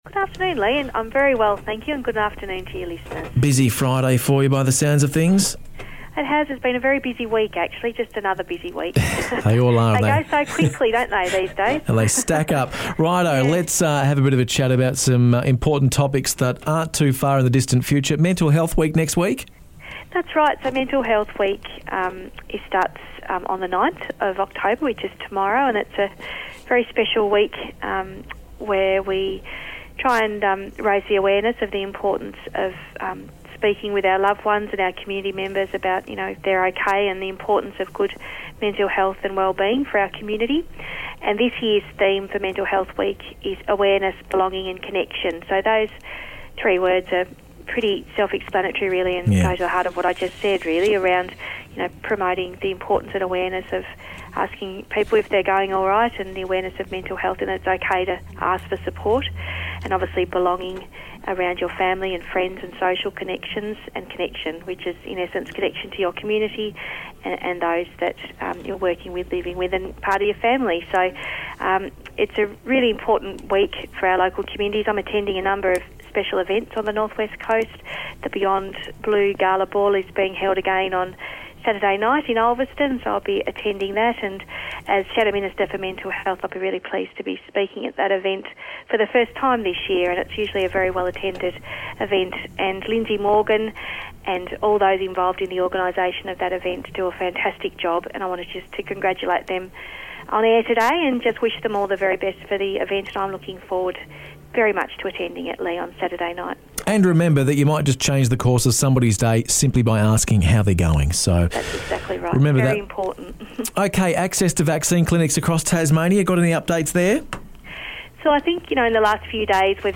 Mental Health Week, access to vaccine clinics across Tasmania, a smoking cessation program in Circular Head and Parliament next week are all open for discussion today with Deputy Opposition Leader Anita Dow.